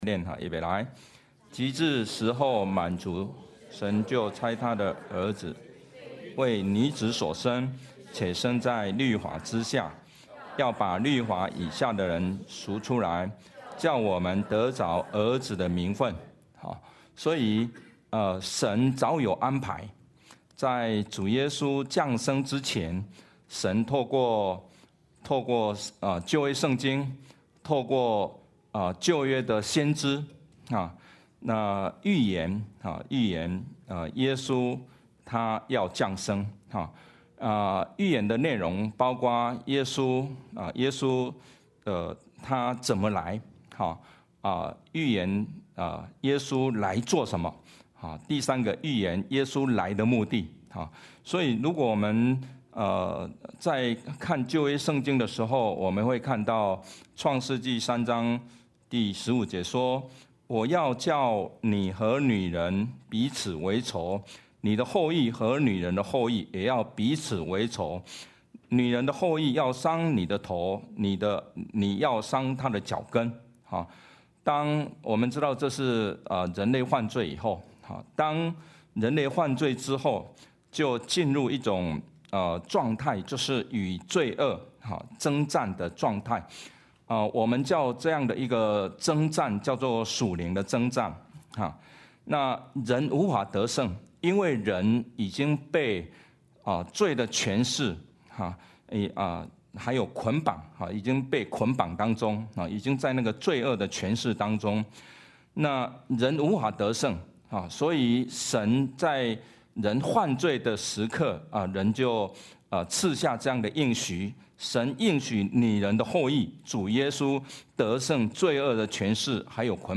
Passage: Isaiah 40:1–11 Service Type: 主日证道 Download Files Notes « 一杯凉水（事奉意义的再思